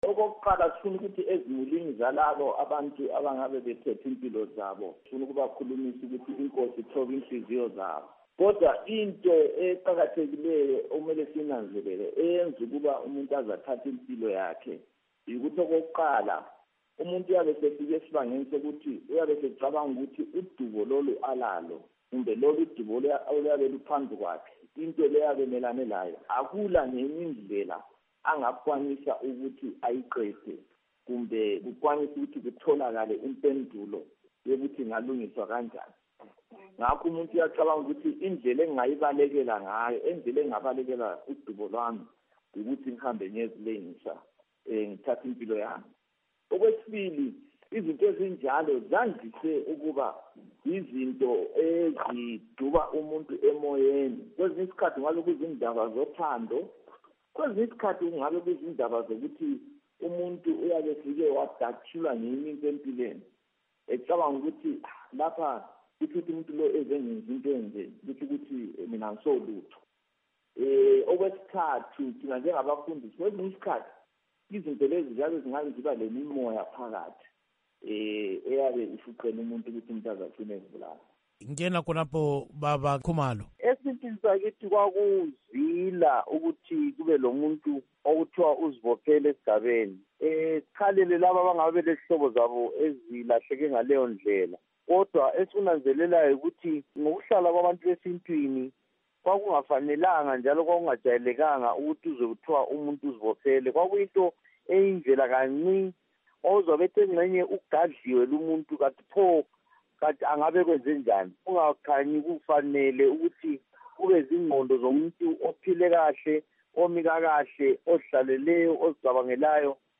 Ingxoxo yesiga sokuzibulala